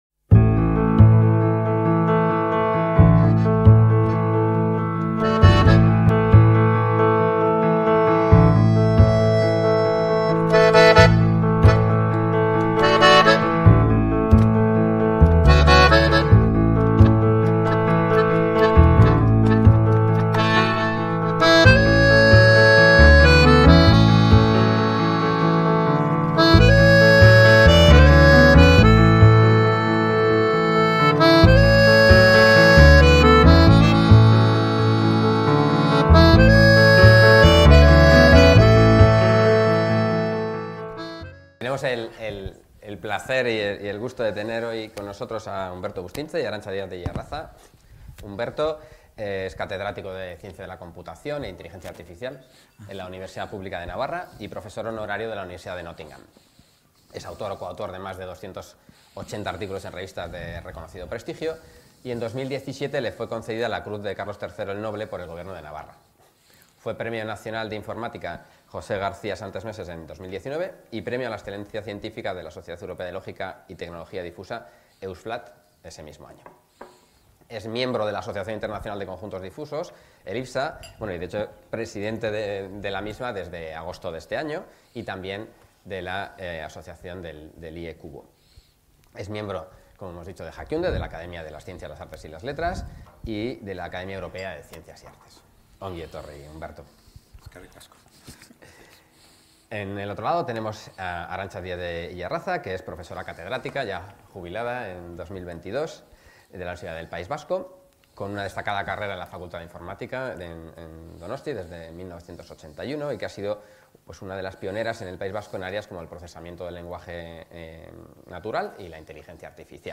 Debate